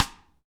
Index of /90_sSampleCDs/Best Service - Real Mega Drums VOL-1/Partition H/DRY KIT 2 GM